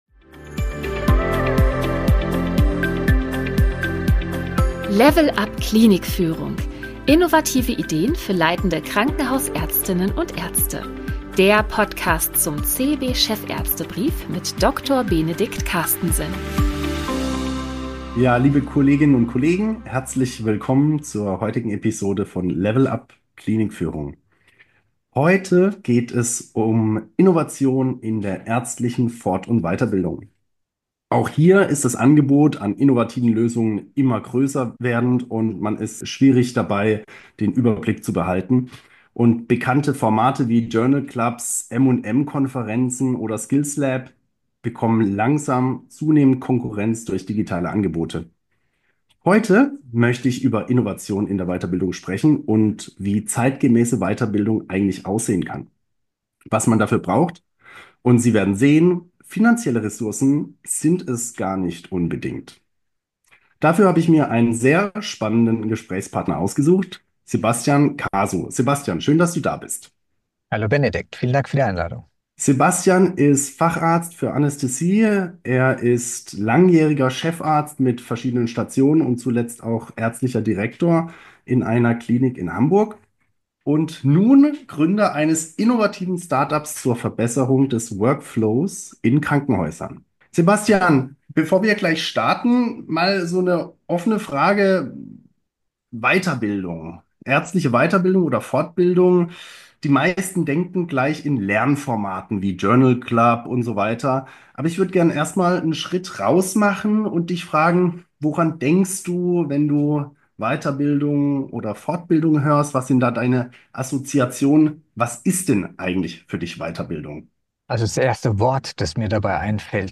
Ein inspirierendes Gespräch über die Weiterentwicklung im Gesundheitswesen und die Rolle von Führungskräften im Bildungsprozess.